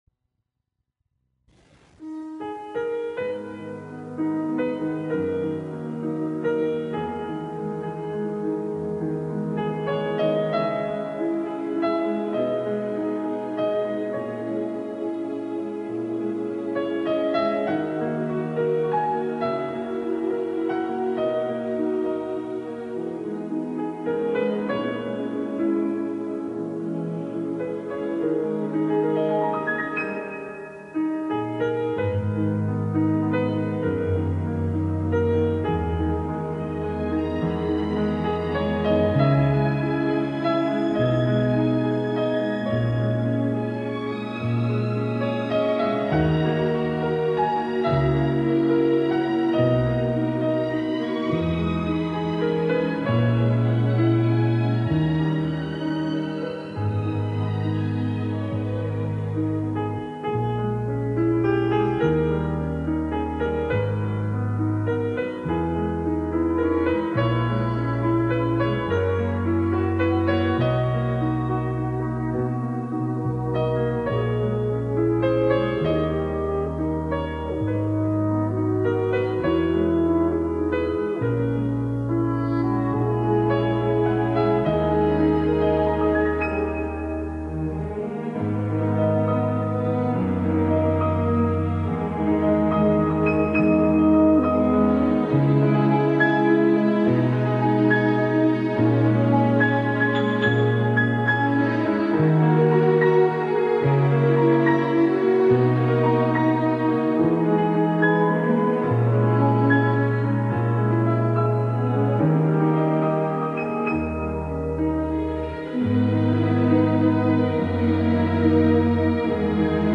Авторы - у рояля!!